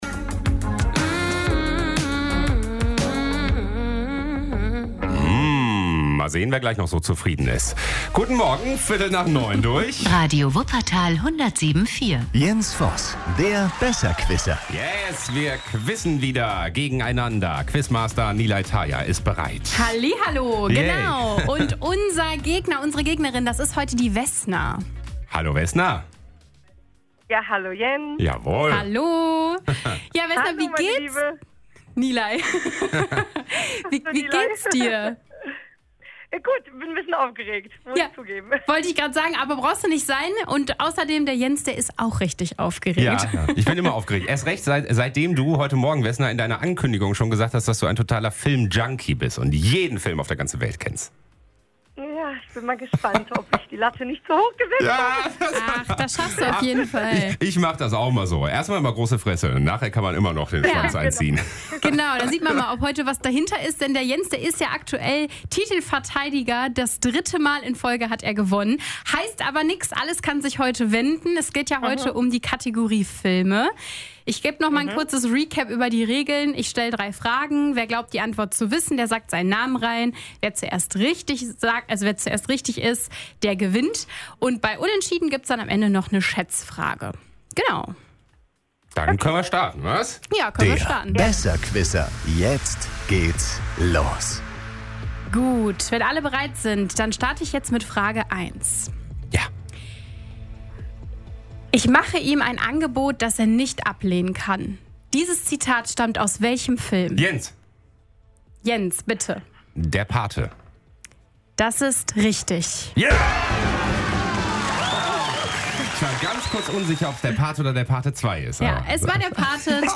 Wer die Antwort weiß, ruft schnell seinen Namen. Wer zuerst richtig antwortet, holt den Punkt.